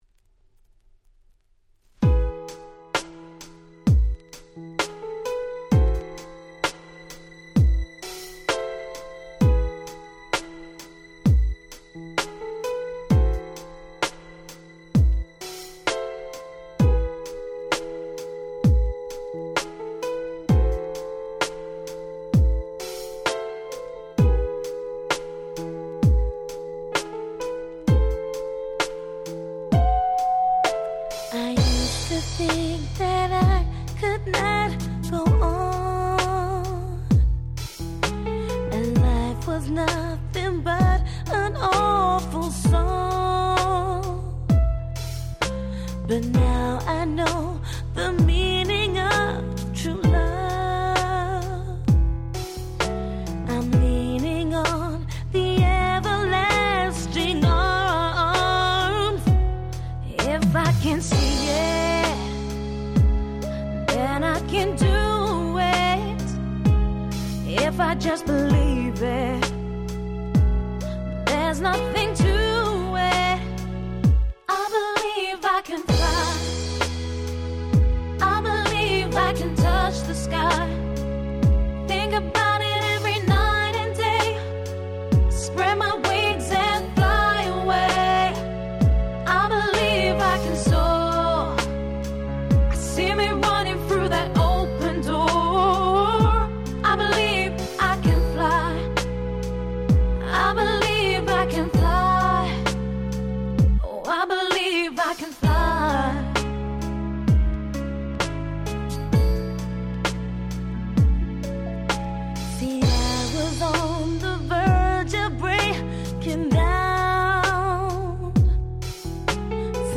Nice Cover R&B !!